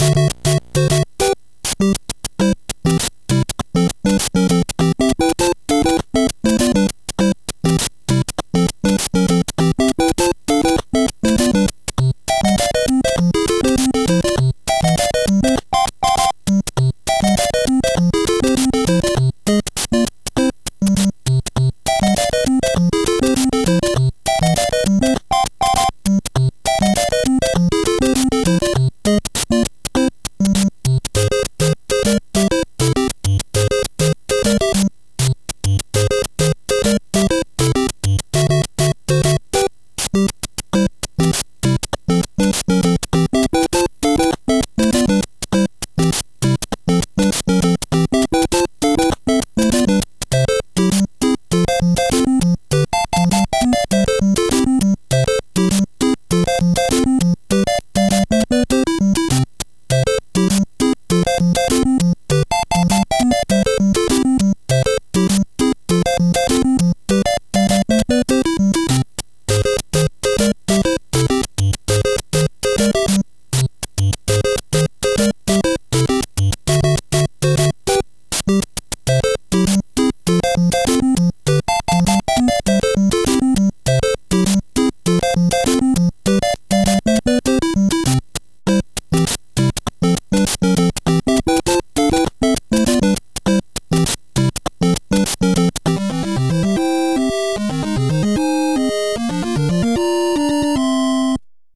BPM100
Audio QualityPerfect (High Quality)
Better quality audio.